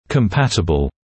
[kəm’pætəbl][кэм’пэтэбл]совместимый, сочетаемый (compatible with)